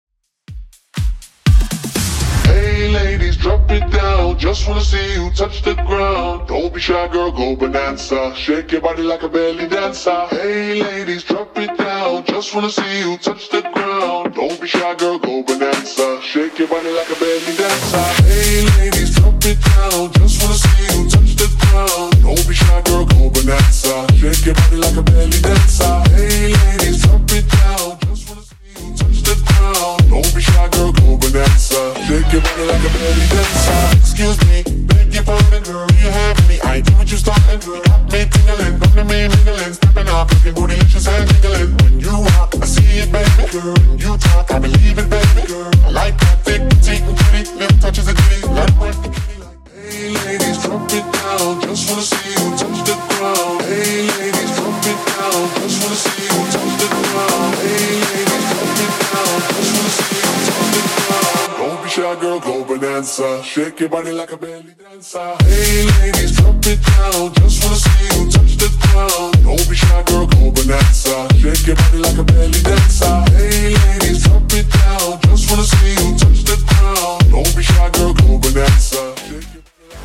Genre: 80's
BPM: 117